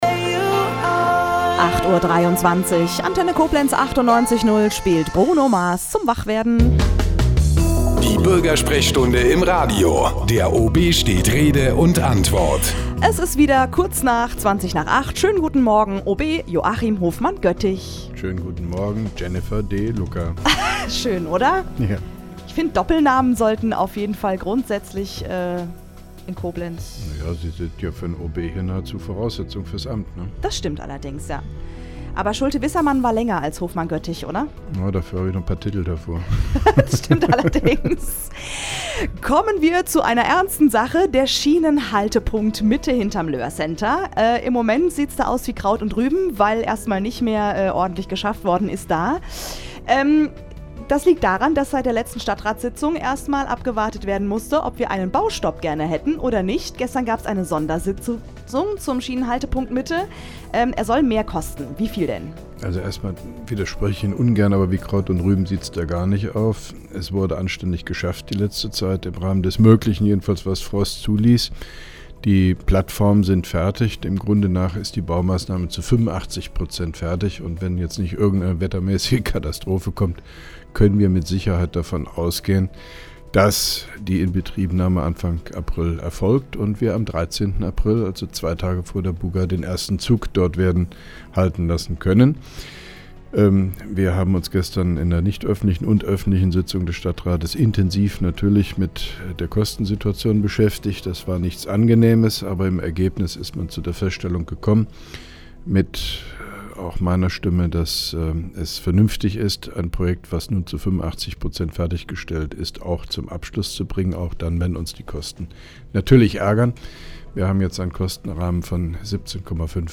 (1) Koblenzer Radio-Bürgersprechstunde mit OB Hofmann-Göttig 18.01.2011